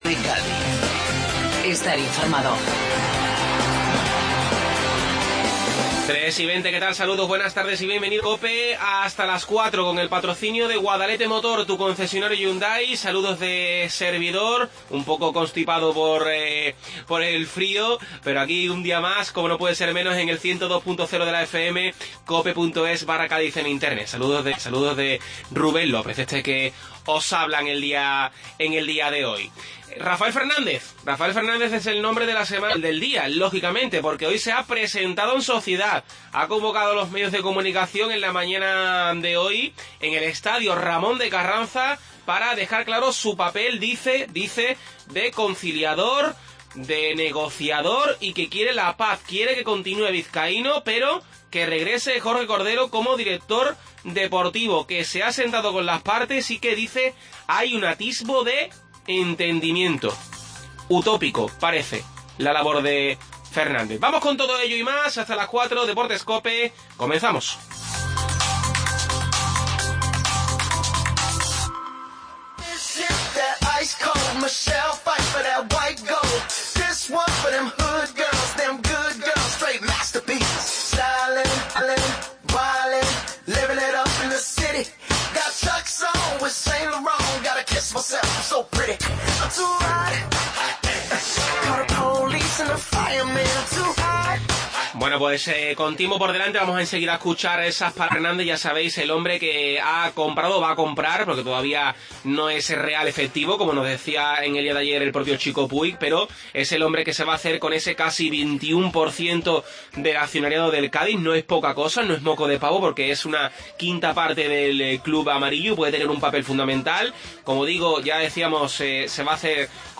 Lo escuchamos y nos concede una entrevista